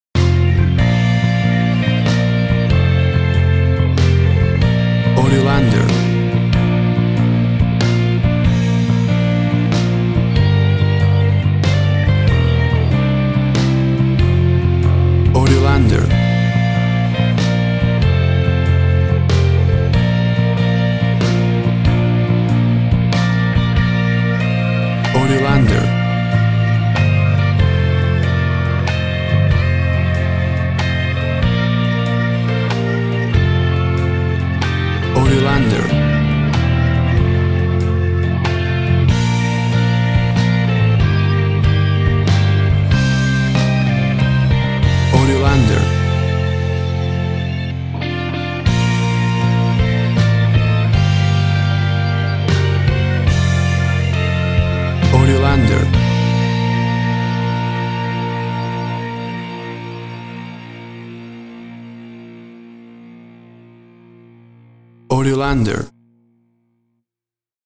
played in a big indie rock style! Regal and triumphant
Tempo (BPM) 100